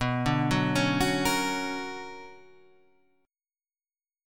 BmM9 chord {7 9 8 7 7 9} chord